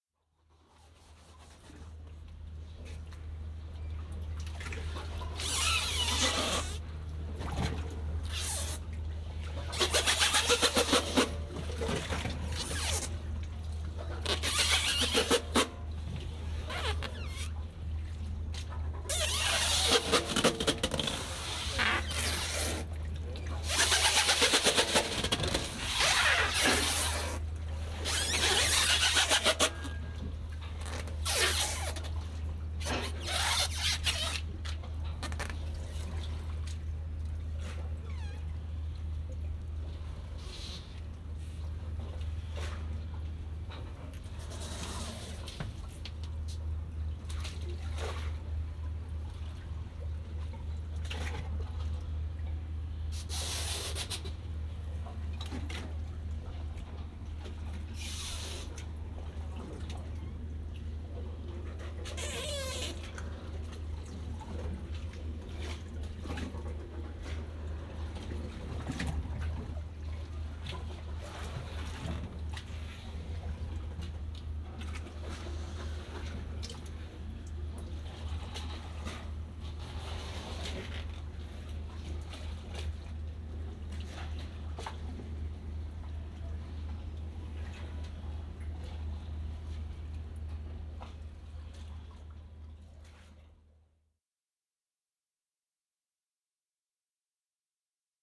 Unprocessed Field Recordings 1999–2007, Germany and Finland.
7. Hamburg, Germany
Metallic creaks, groans, squeaks, thuds and rattles dominate the foreground, while the background often provides a subtle layer of sound that gives the recordings additional depth.
All are of the highest technical quality and possess a richness that makes the listening experience a real pleasure.